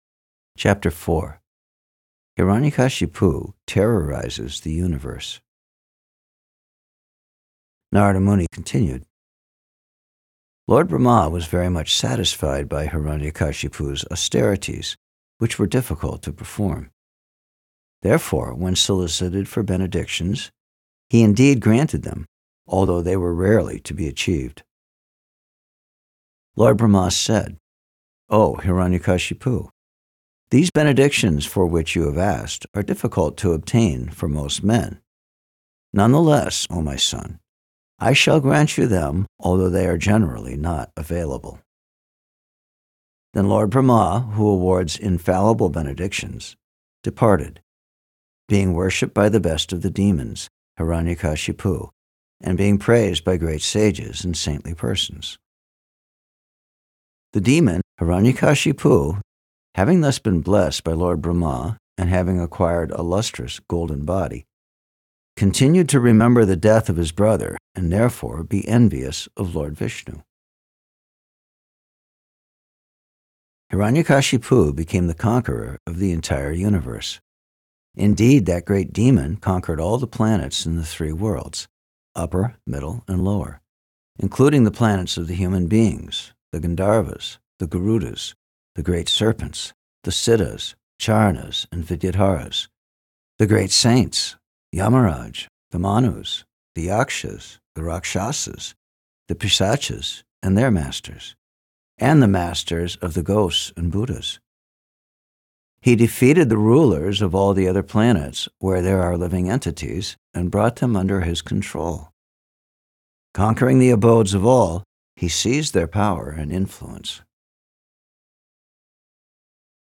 Thank you very much for this clear reading =) Really appreciate it.
04-Ch-4-SB-7th-Canto-Verses-Only.mp3